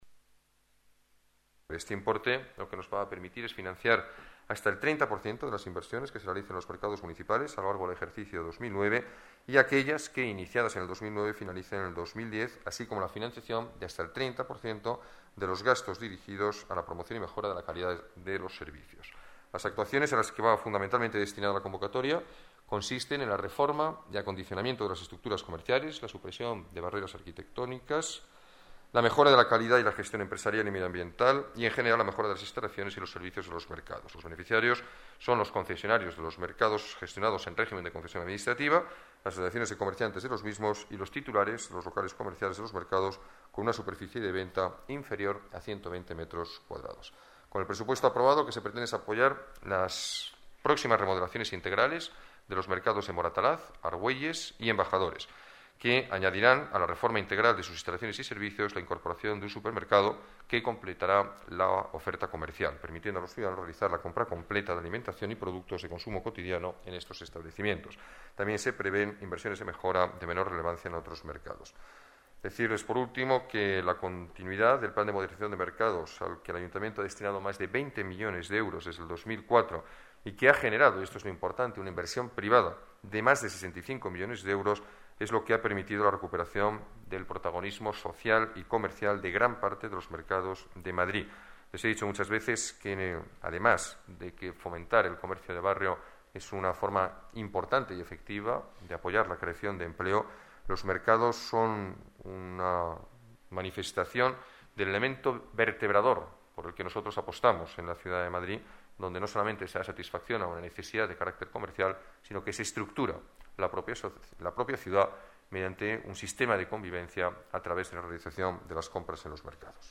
Nueva ventana:Declaraciones alcalde, Alberto Ruiz-Gallardón: modernización mercados